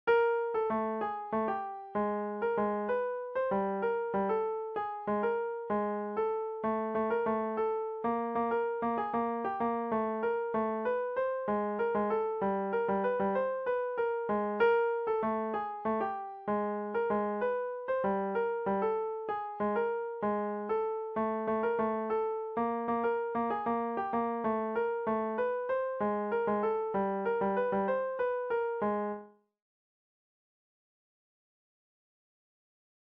Here one will hear the same rhythms over and over regardless of notation.The notes are all the same length but the beats change a little.The duration of each note is the same but won't necessarily look the same.